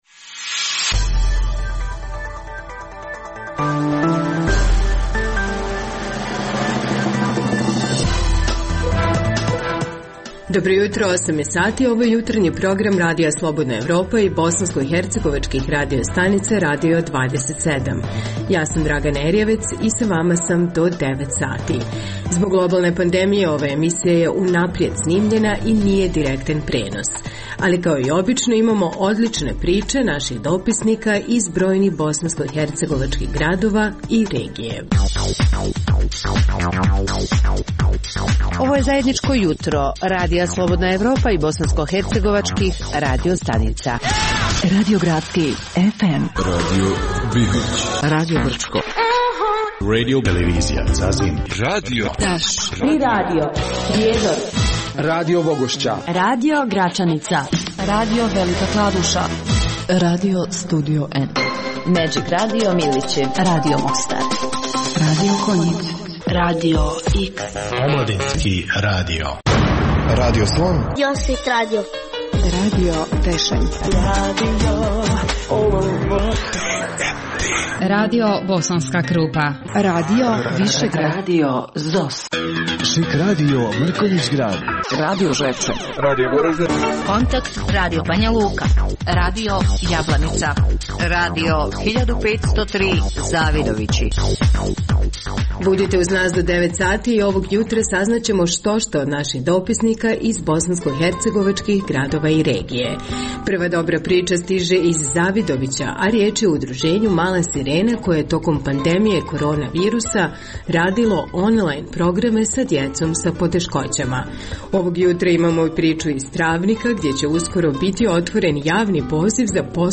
Zbog globalne pandemije ova emisija je unaprijed snimljena i nije direktan prenos. Poslušajte neke od zanimljivih priča iz raznih krajeva Bosne i Hercegovine.